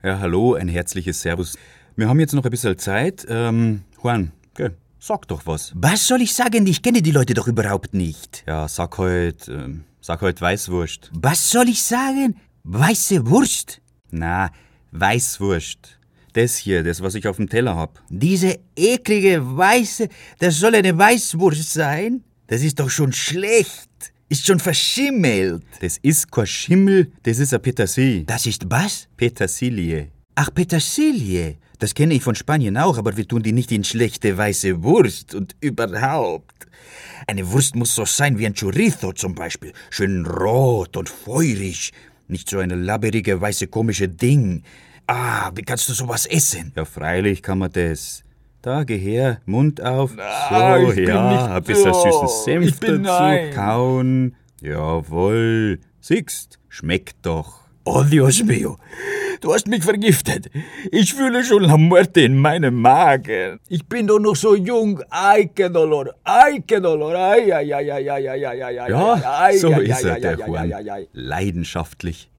Sprechprobe: Industrie (Muttersprache):
voice over artist: german, brasilian, portuguese.